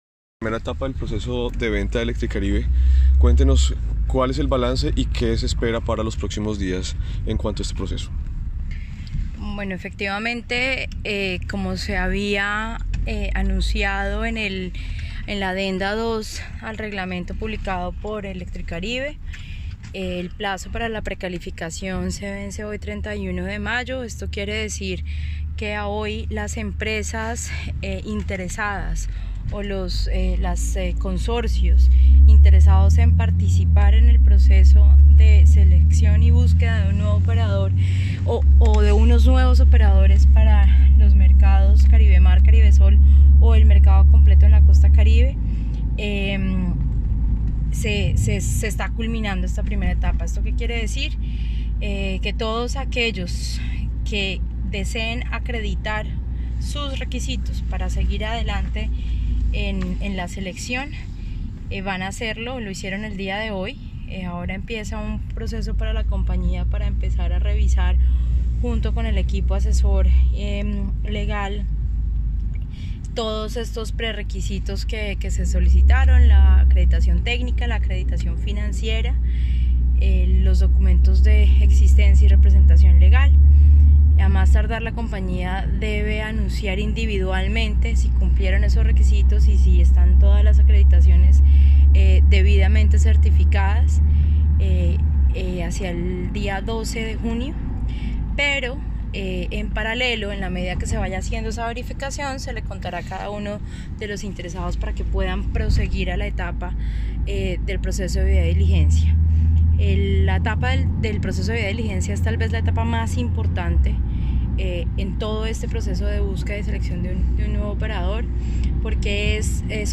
Se adjunta audio con declaraciones de la superintendente de Servicios Públicos Domiciliarios, Natasha Avendaño García